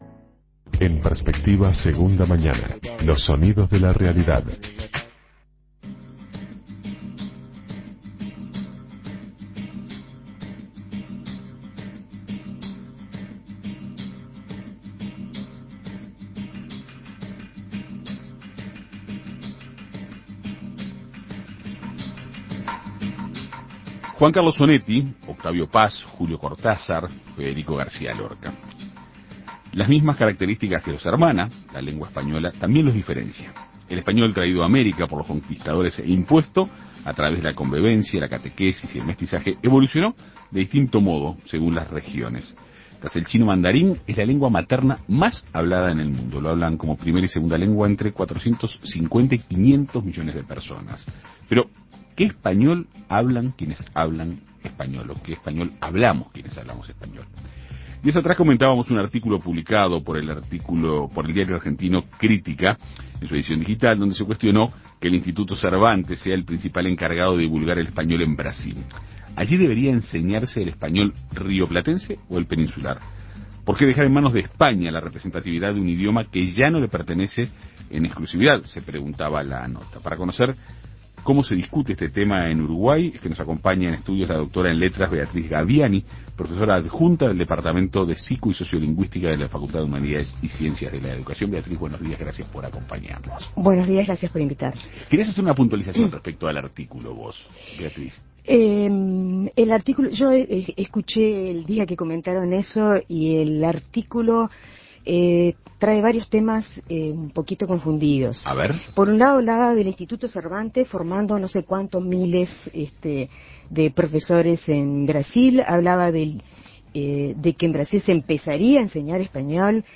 entrevistó